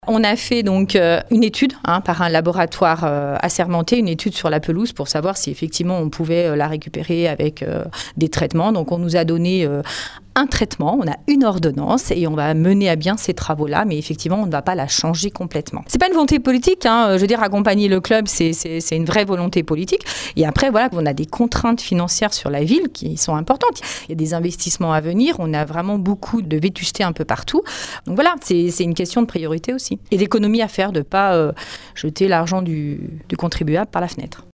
On écoute les explications de Catherine Allard, elle est Adjointe déléguée aux sports à la mairie d’Annecy